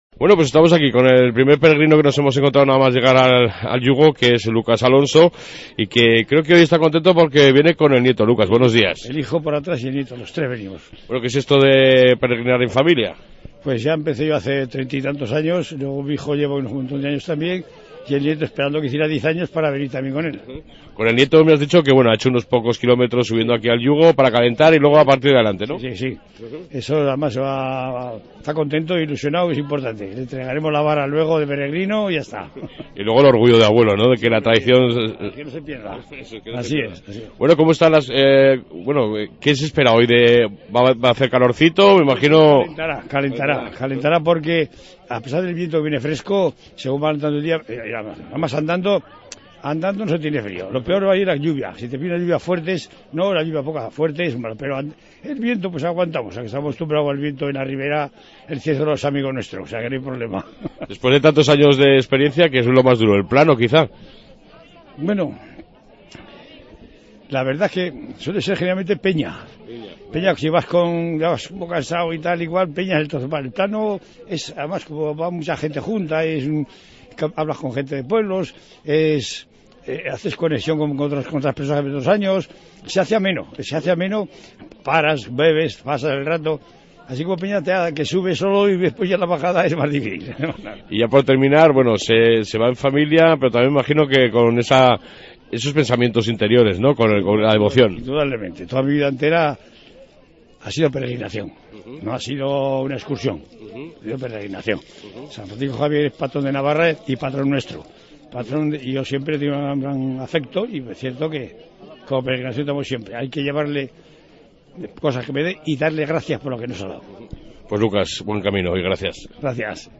AUDIO: Reportaje sobre la javierada 2015 en el Yugo